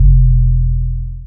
Southside 808 (7).wav